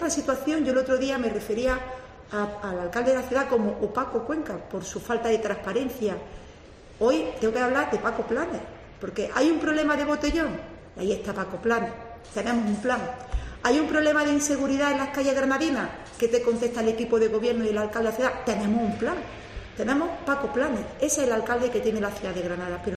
Eva Martín, coordinadora del PP en el Ayuntamiento de Granada